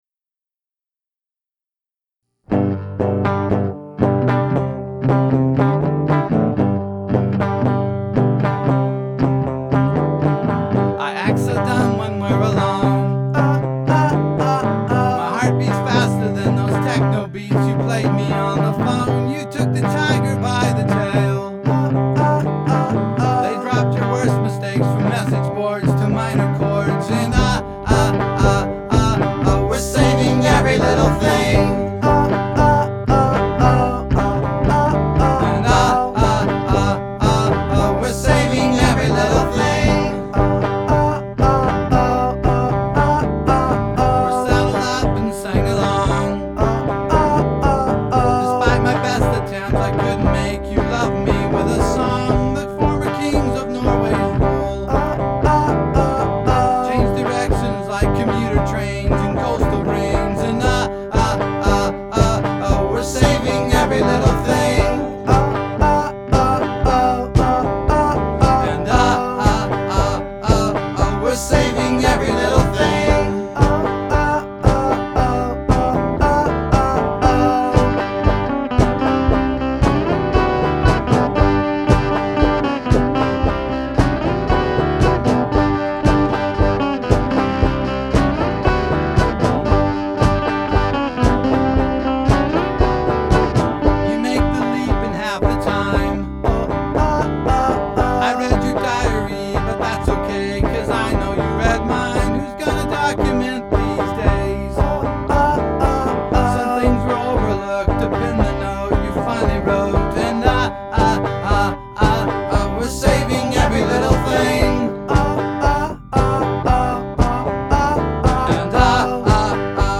lo-fi band